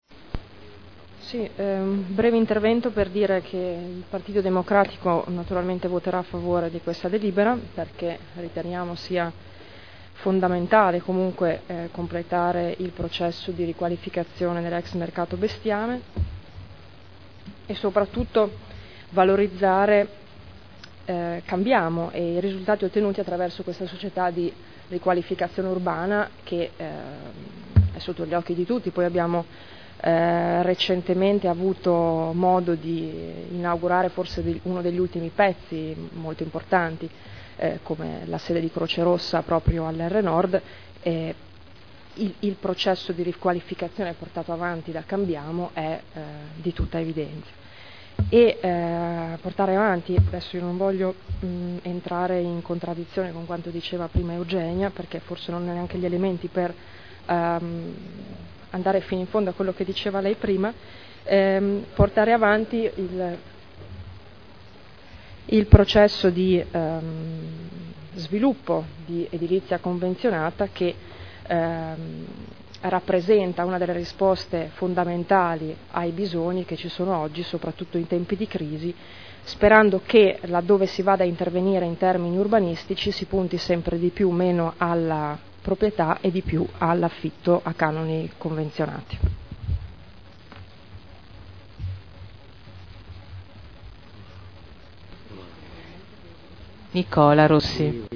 Giuliana Urbelli — Sito Audio Consiglio Comunale
Dichiarazione di voto su proposta di deliberazione. Conferimento alla Società di Trasformazione Urbana CambiaMo S.p.A. di lotto edificabile presso il comparto Ex Mercato Bestiame – Approvazione